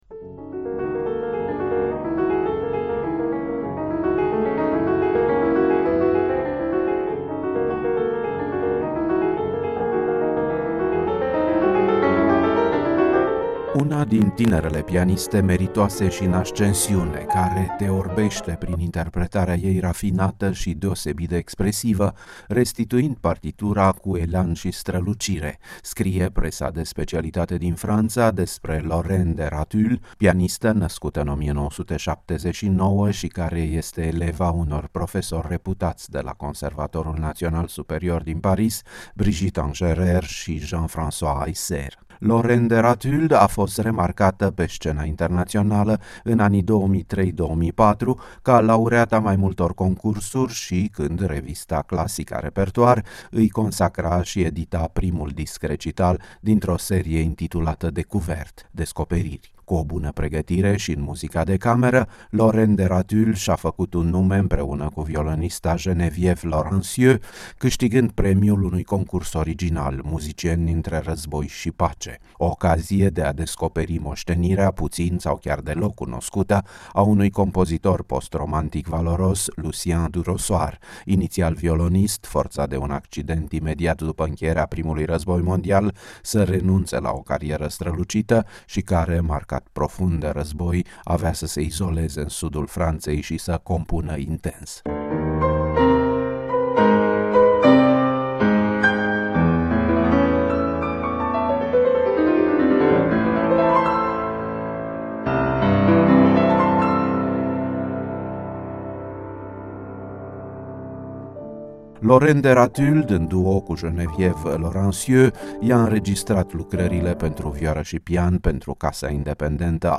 O pianistă franceză în recital la Chișinău